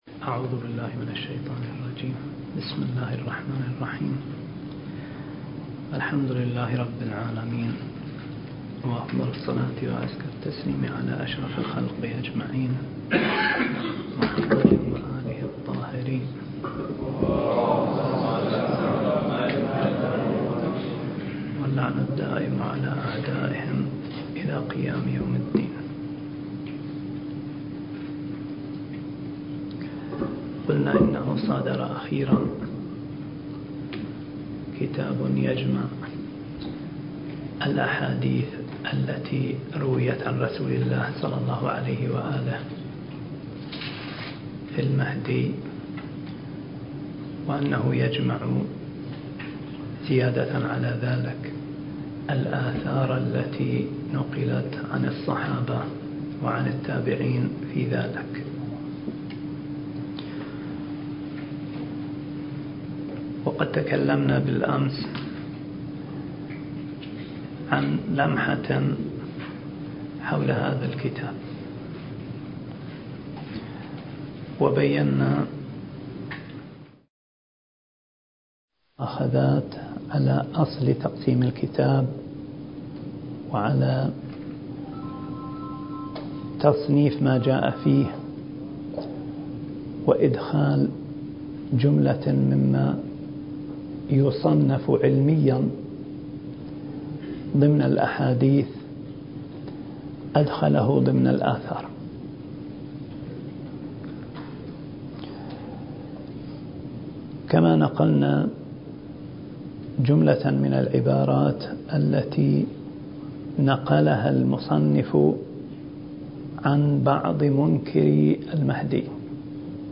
الدورة المهدوية الأولى المكثفة (المحاضرة التاسعة عشر)